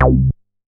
MoogAlicious A.WAV